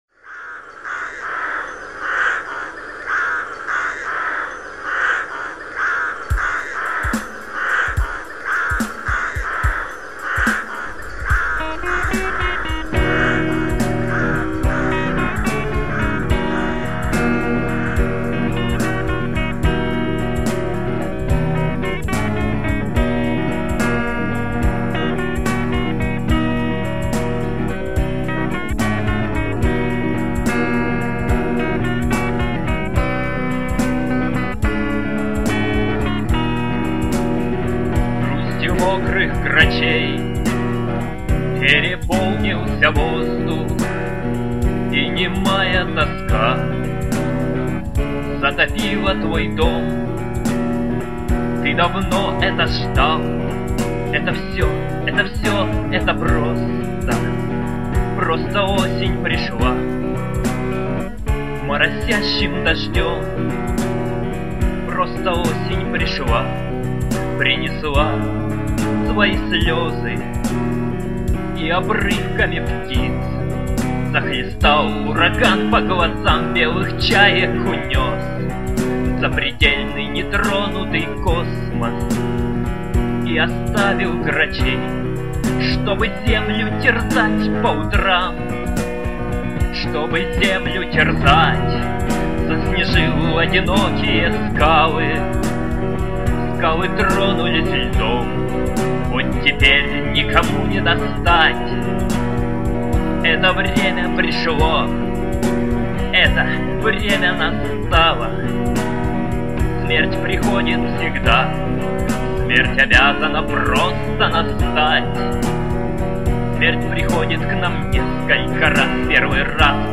Музыка, аранжировка, исполнение